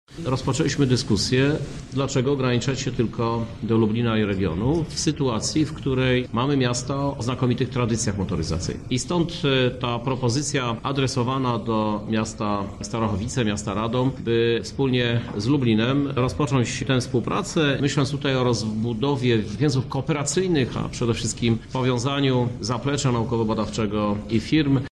O rozszerzeniu klastra mówi prezydent Lublina, Krzysztof Żuk: